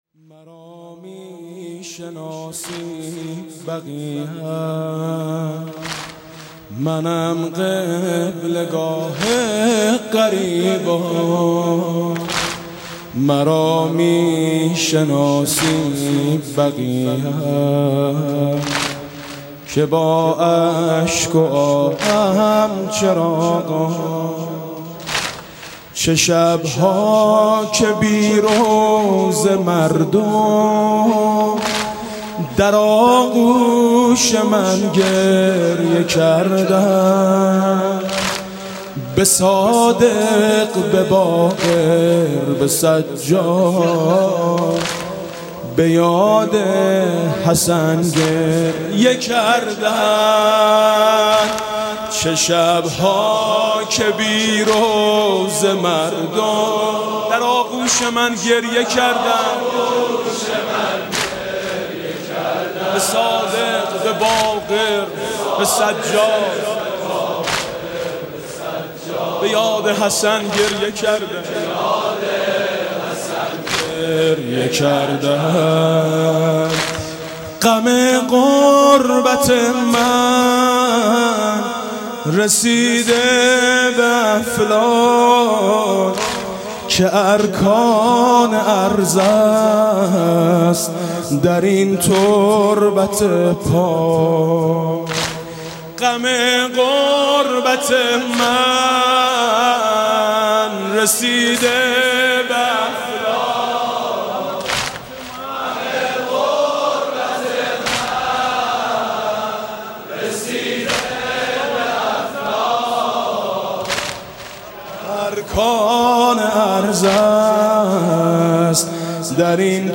«از زبان قبرستان بقيع» واحد: مرا میشناسی بقیعم، منم قبله گاه غریبان